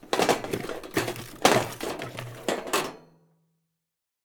grilling
coal.ogg